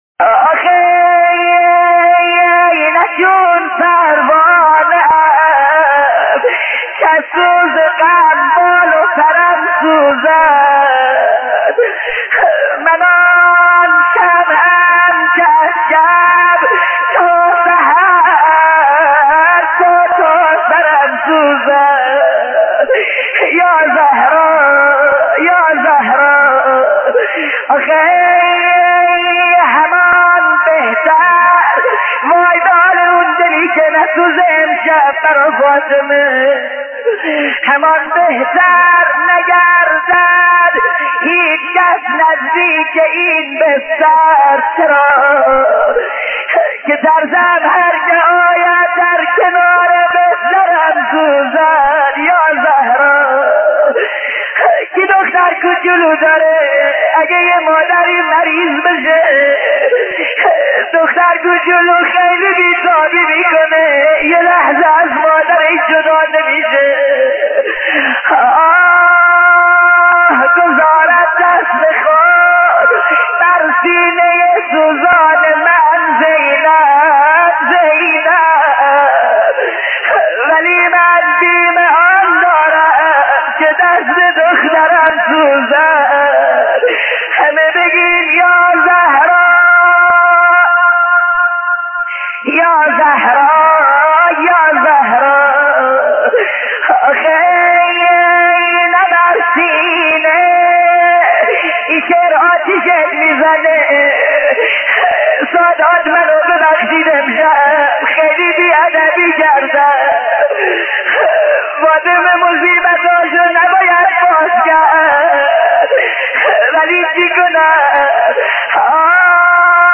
روضه جانسوز و غمگین شهادت حضرت فاطمه (س)